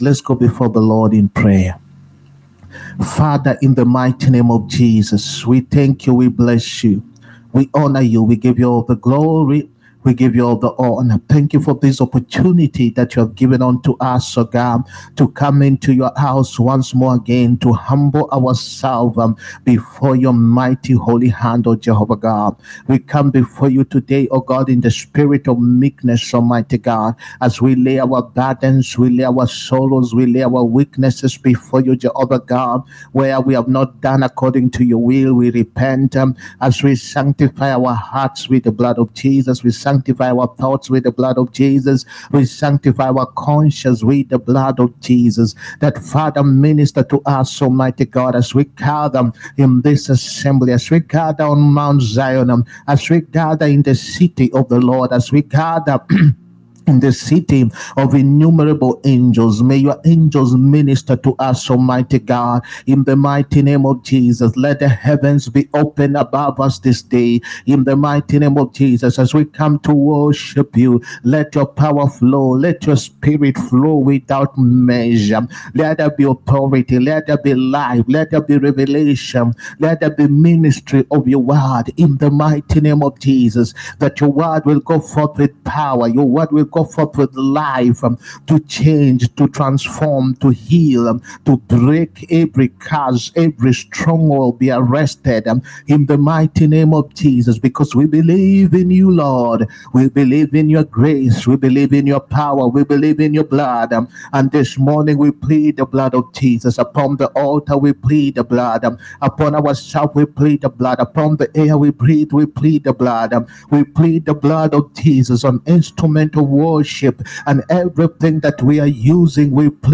SUNDAY ANOINTING SERVICE. GOD’S DIVINE ENCOUNTER. 1ST JUNE 2025.